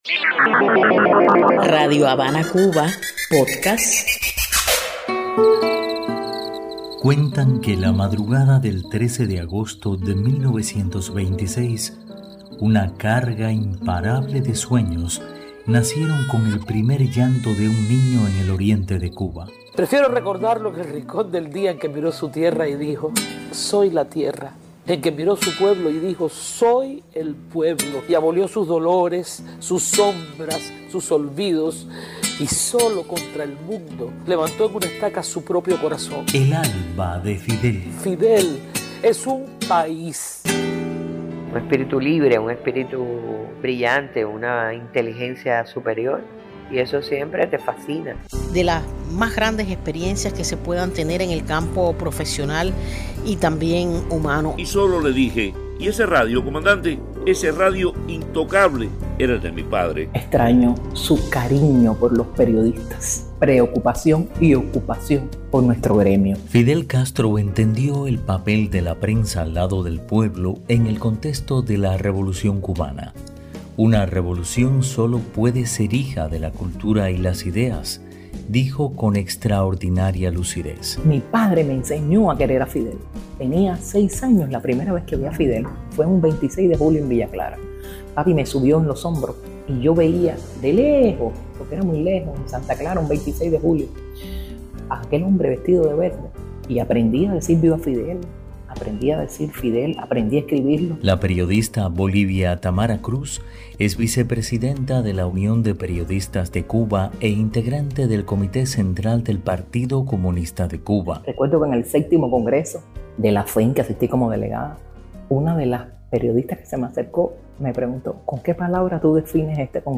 Testimonios de reconocidos periodistas cubanos sobre Fidel Castro.